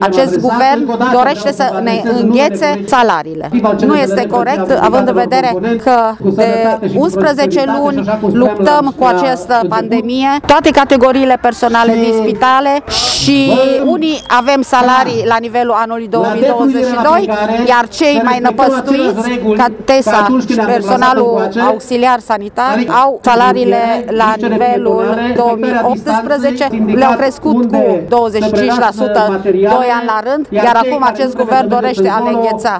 Zeci de sindicaliști au protestat astăzi în fața Prefecturii Mureș